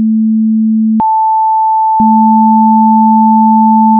dbloctave.wav